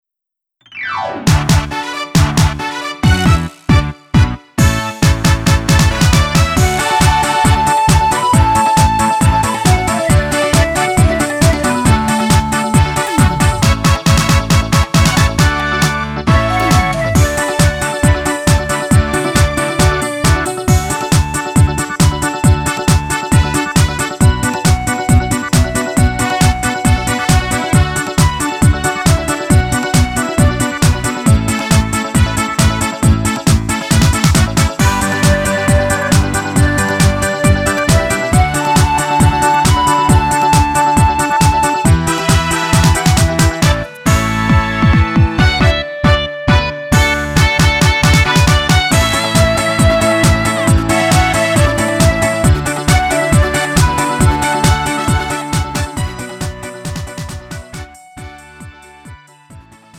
음정 -1키 3:29
장르 구분 Lite MR